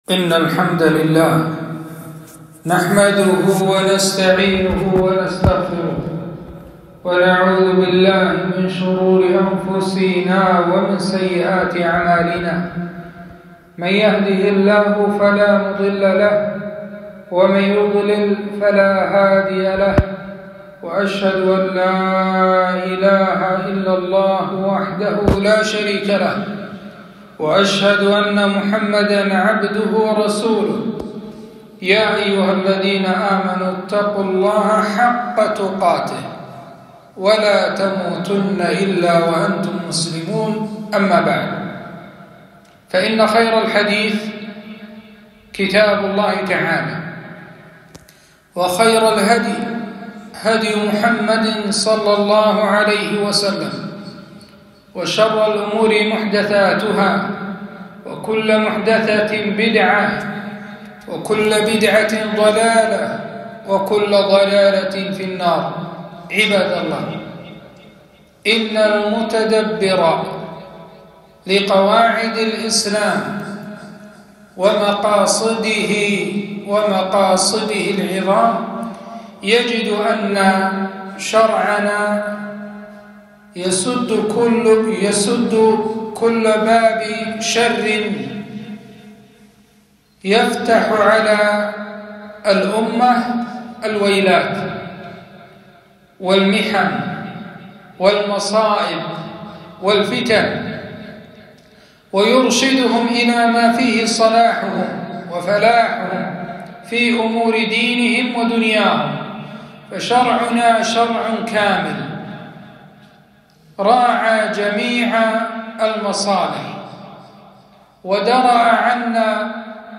خطبة - وجوب توقير ولي الأمر وإكرامه